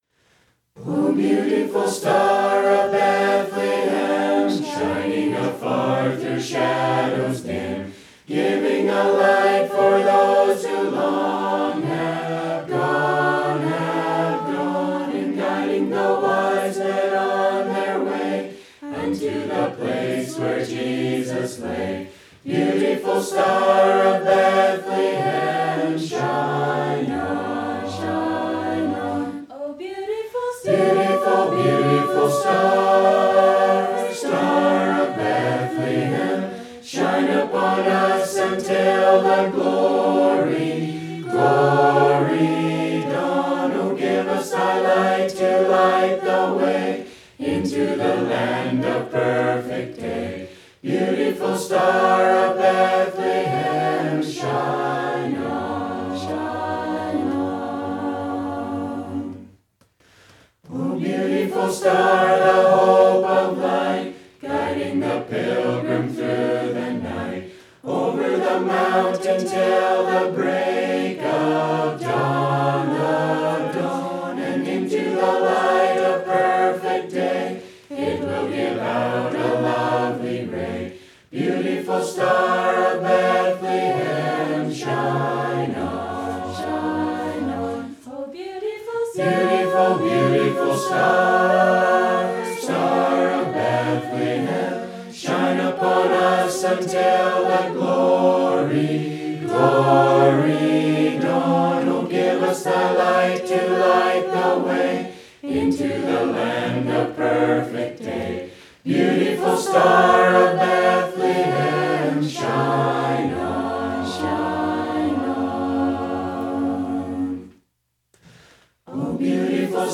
2021-HMB-Youth-Christmas-Program.mp3